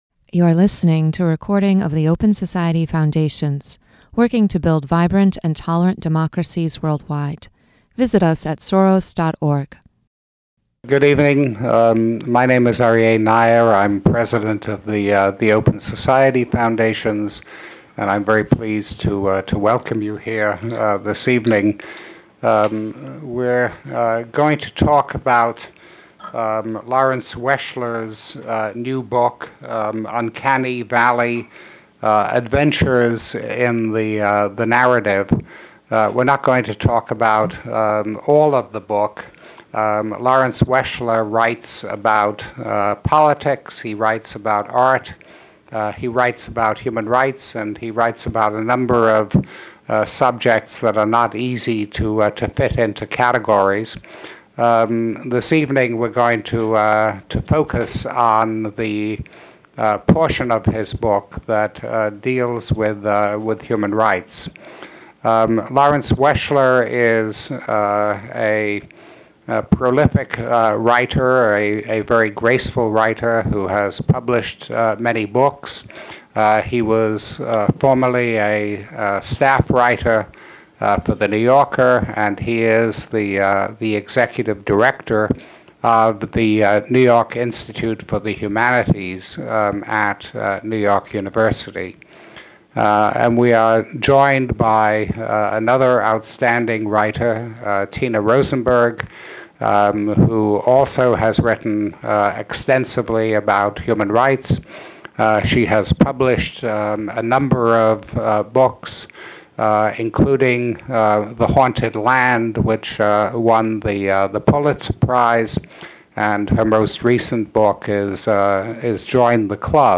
This Open Society Foundations event marks the launch of Uncanny Valley: Adventures in the Narrative, a collection of essays by journalist Lawrence Weschler.
These parts of the book form the basis for this conversation with Aryeh Neier and Tina Rosenberg.